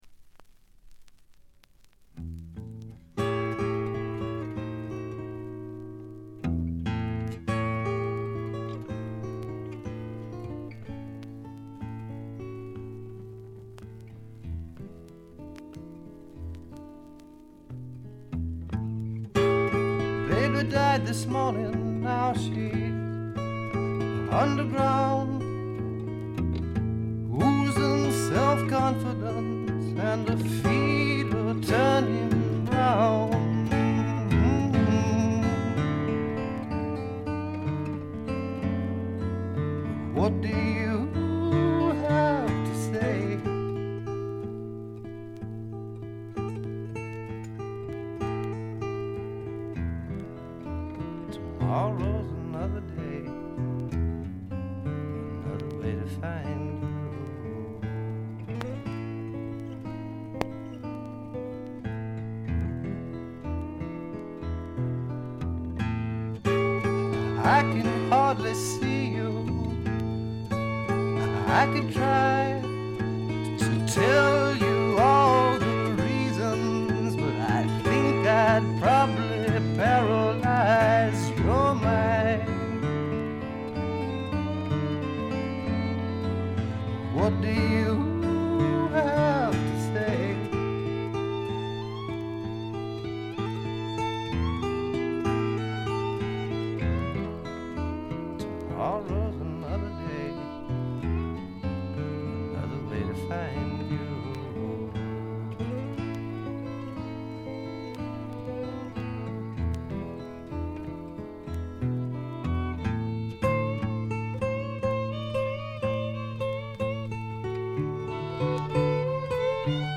A5序盤、B2冒頭でプツ音。
試聴曲は現品からの取り込み音源です。